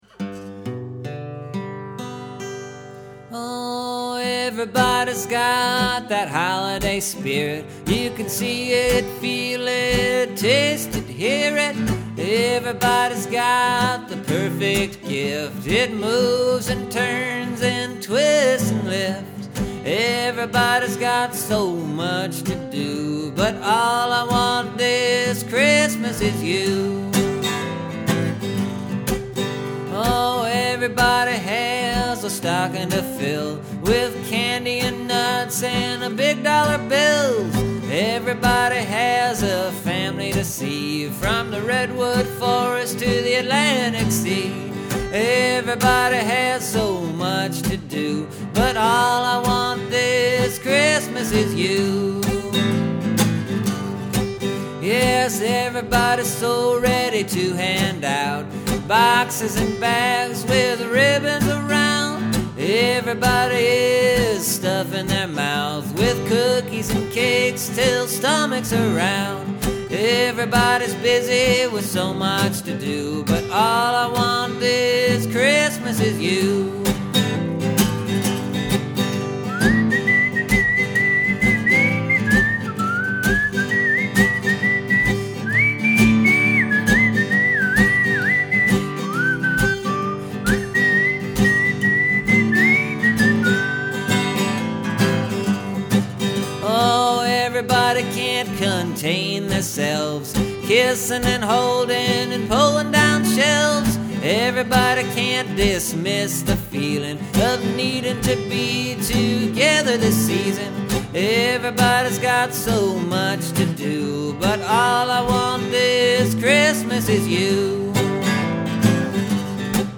And this is a holiday song.
It’s sort of cheesy and somewhat sentimental, but not too much of either to be considered too terrible, I guess.
It’s your basic strummed chords in the key of G and your basic singing of a song like I usually sing a song.